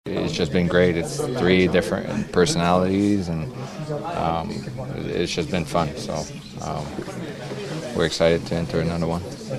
Letang says “the big three” enjoy playing hockey together.